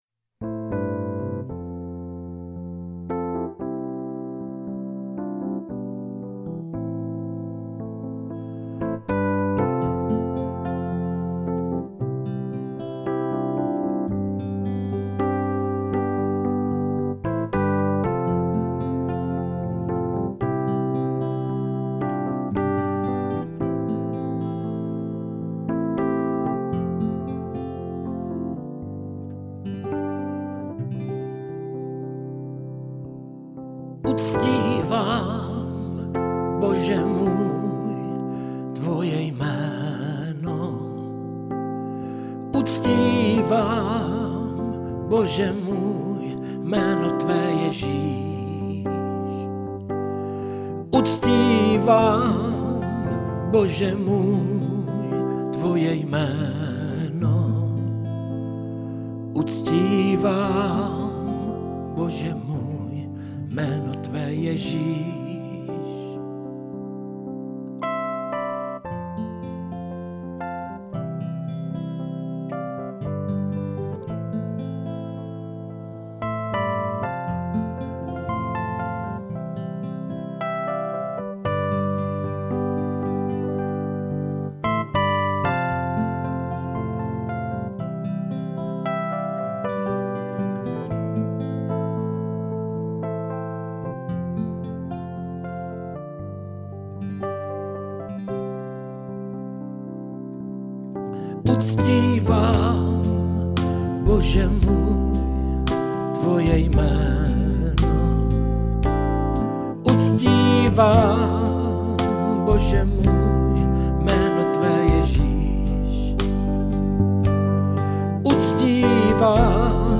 Křesťanské písně
Písně ke chvále a uctívání